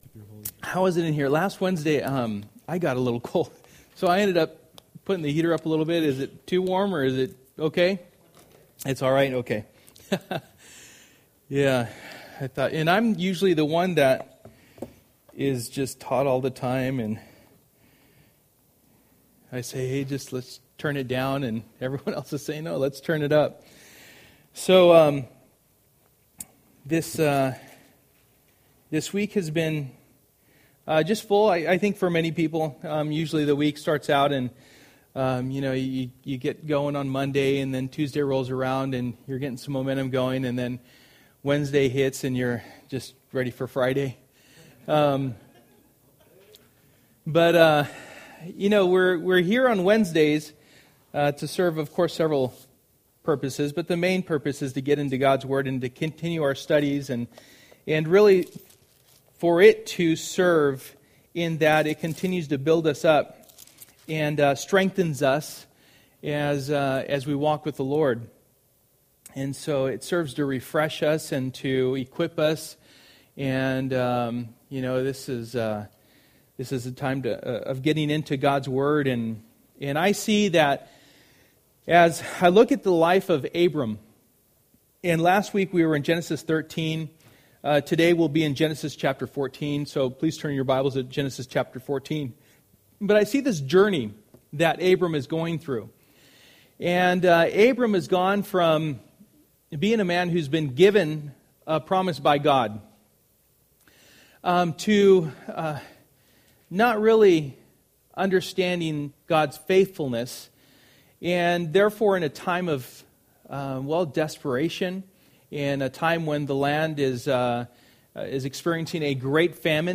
Through the Bible Passage: Genesis 14:1-24 Service: Wednesday Night %todo_render% « A New and Superior Priesthood God or Counterfeit God.